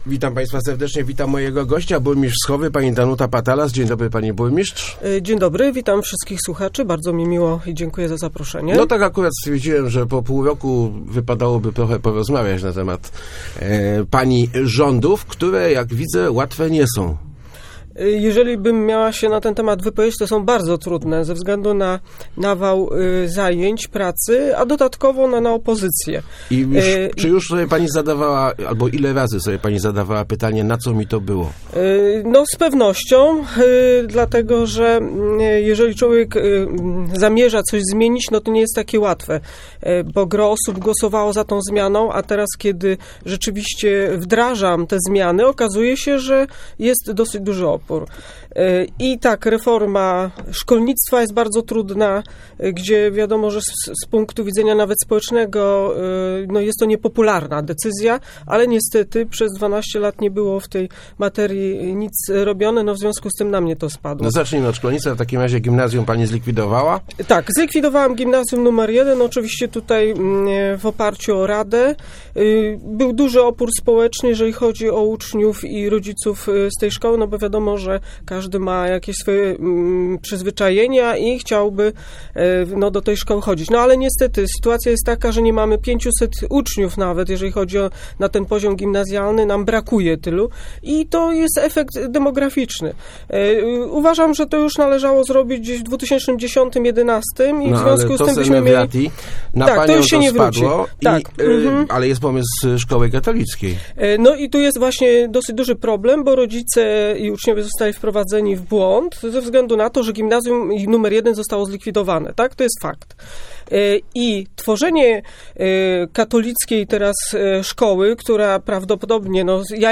Nie jestem przeciwna utworzeniu Zespo�u Szkó� Katolickich, cho� widz� tu wiele problemów – mówi�a w Rozmowach Elki burmistrz Wschowy Danuta Patalas.